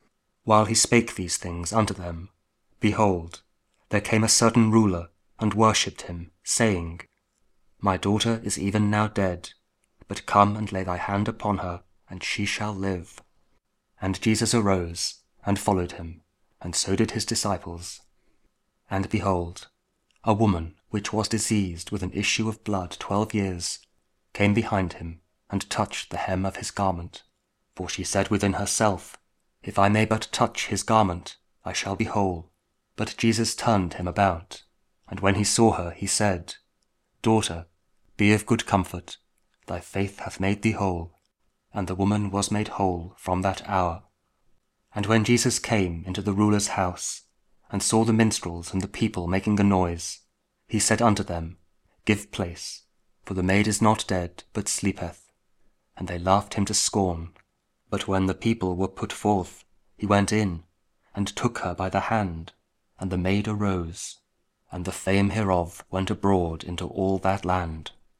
Matthew 9: 18-26 – Week 14 Ordinary Time, Monday (King James Audio Bible KJV, Spoken Word)